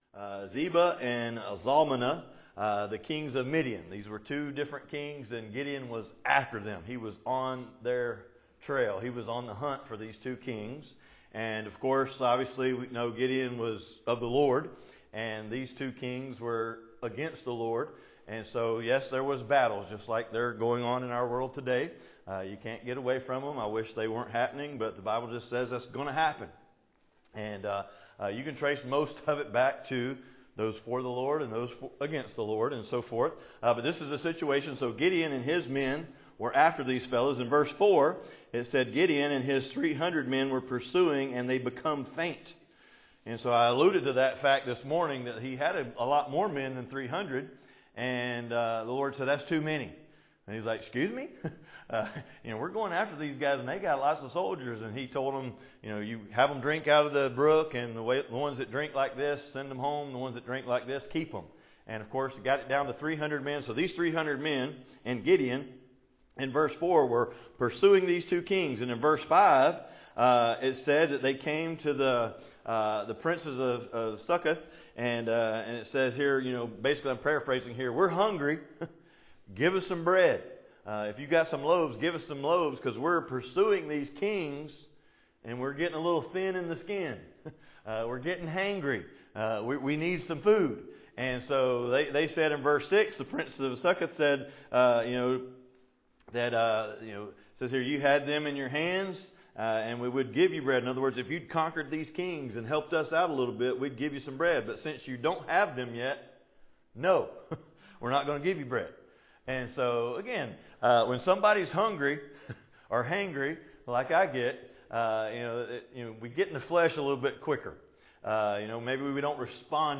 Passage: Judges 8:18 Service Type: Evening Service